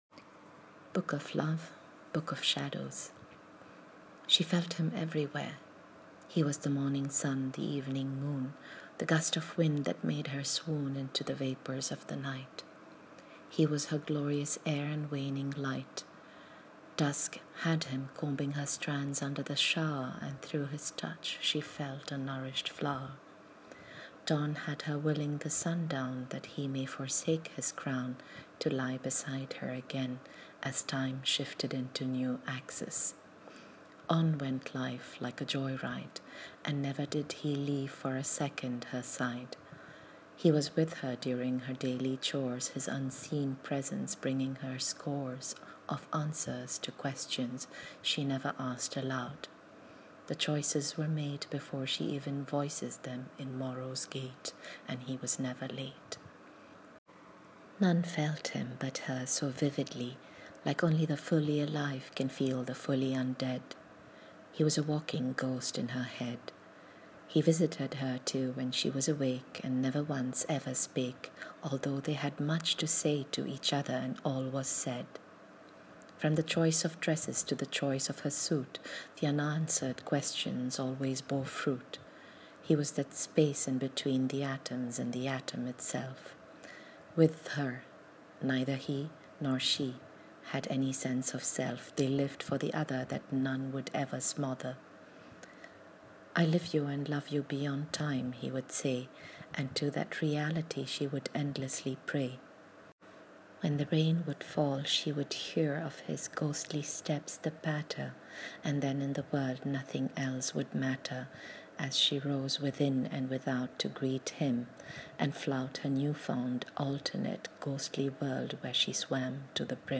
Reading of the short story: